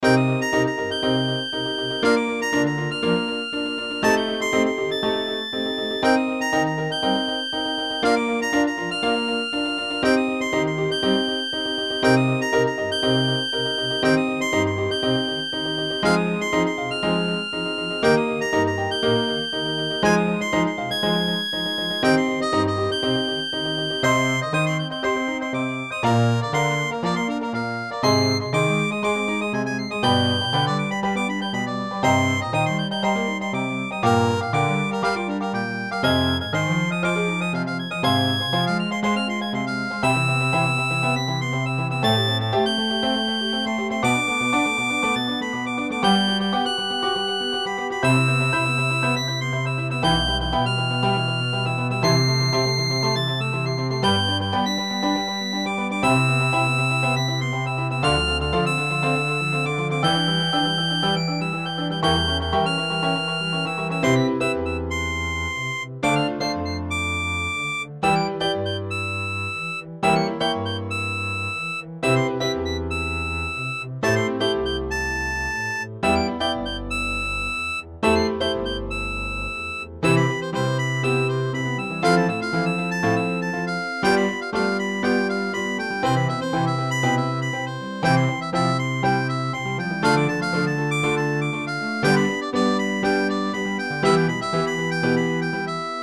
ハーモニカ、ピアノ、コントラバス
BGM